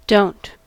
Uttal
Alternativa stavningar (eye dialect) don' Uttal US: IPA : /ˈdoʊnt/ UK: IPA : /ˈdəʊnt/ US: IPA : [doʊ̯(ʔ)t̚] Ordet hittades på dessa språk: engelska Ingen översättning hittades i den valda målspråket.